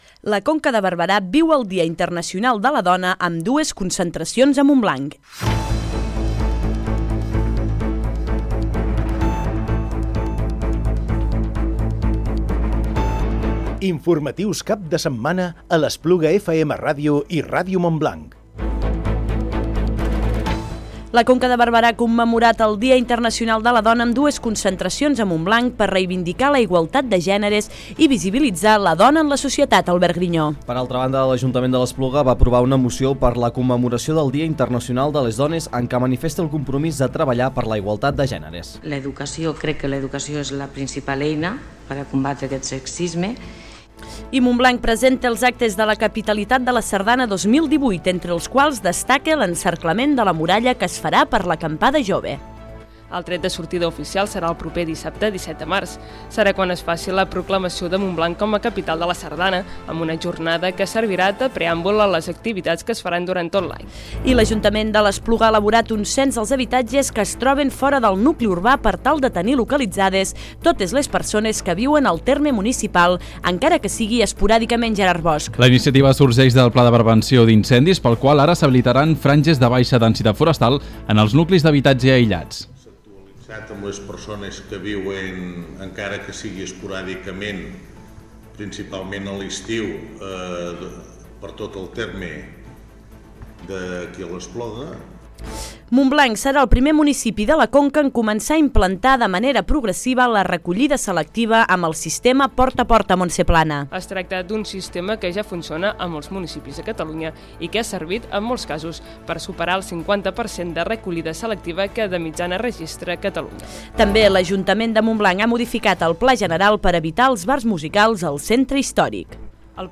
Informatiu Cap de Setmana del 10 i 11 de març del 2018